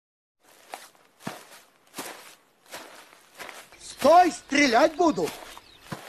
Звуки криков
Крик мужчины Стой, стрелять буду